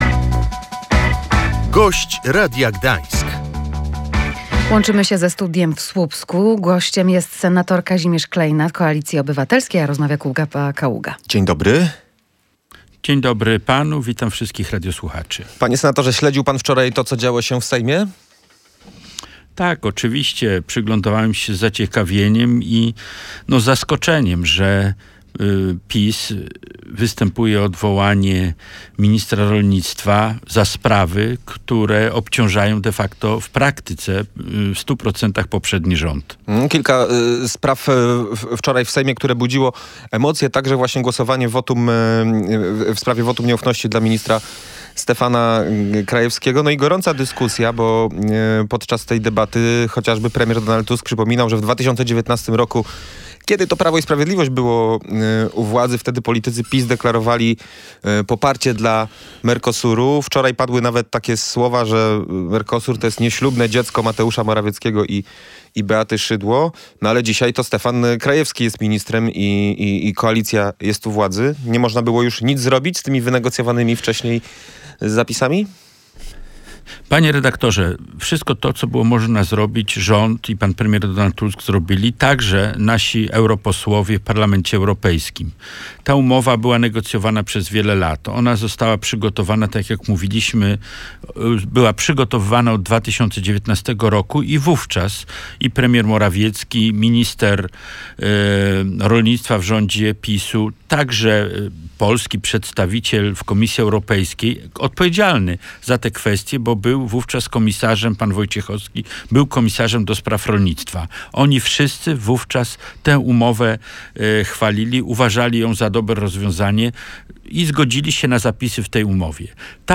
Przebieg trasy Via Pomerania z Ustki do Bydgoszczy powinien być znany do końca roku – zapewniał w Radiu Gdańsk senator Kazimierz Kleina z Koalicji Obywatelskiej.
Gość Radia Gdańsk